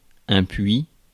Ääntäminen
Synonyymit (slangi) anus Ääntäminen France: IPA: [ɛ̃ pɥi] Tuntematon aksentti: IPA: /pɥi/ Haettu sana löytyi näillä lähdekielillä: ranska Käännös 1. kaev Suku: m .